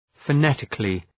Προφορά
{fə’netıklı}